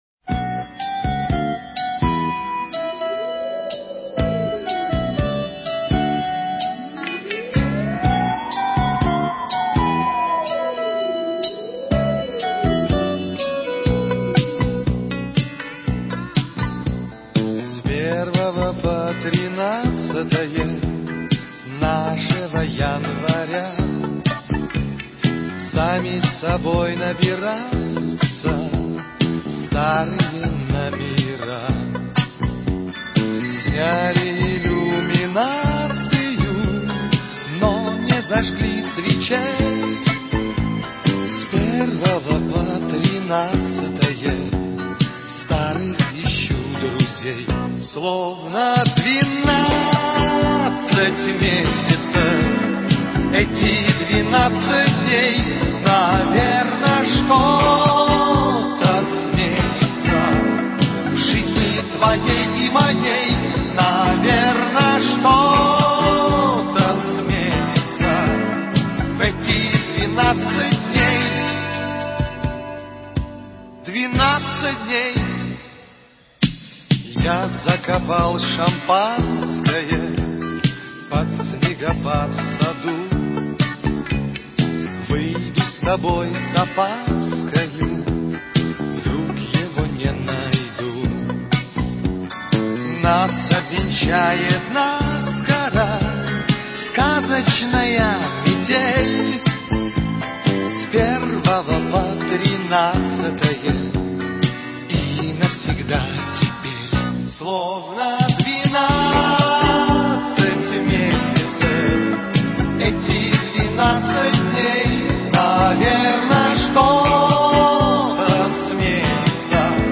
В канун Старого Нового года в программе подкастов газеты "Иркутск" звучат благодарные отзывы наших дорогих слушателей, которые душевно и талантливо подготовили поздравления сотрудникам редакции и дорогим землякам.